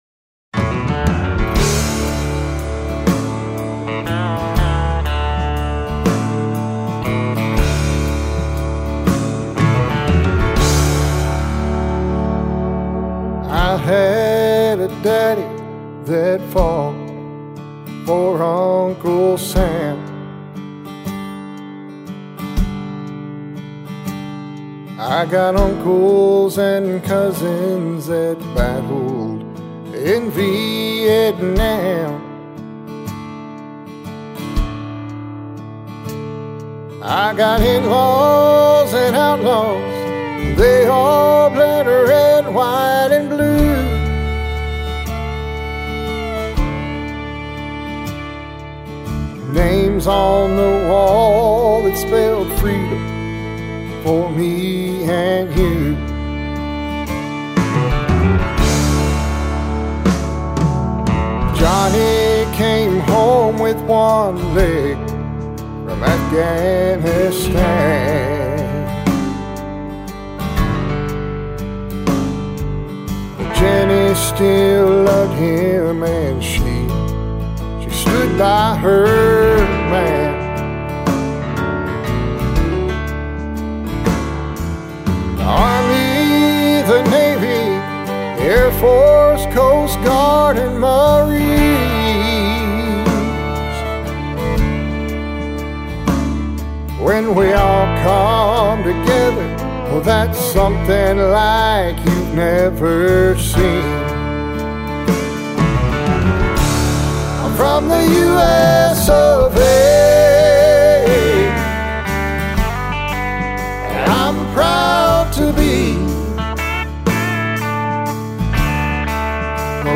Download The MP3 for FREE - CLICK HERE - LIVE VERSION